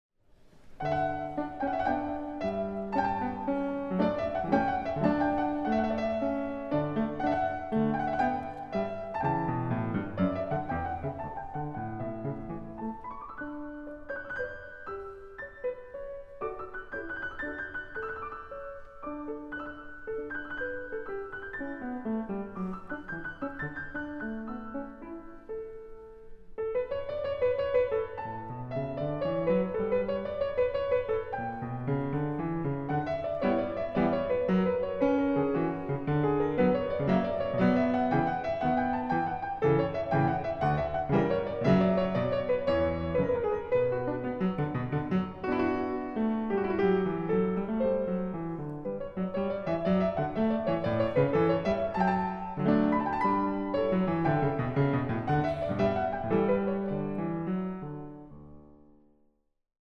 Piano
Recording: Großer Saal, Gewandhaus Leipzig, 2025